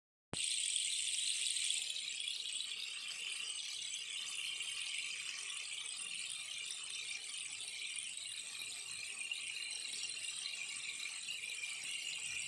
Arbre de pluie professionnel / durée 4 minutes
Idéal pour la méditation ou pour apaiser l'ambiance de la maison, cet instrument diffuse pendant 4 minutes un doux son de pluie tropicale.
A l'intérieur, des billes parcourent un circuit en spirale, ce qui permet à l'arbre de pluie de durer 4 minutes ou plus. Le bois de fabrication est le bambou.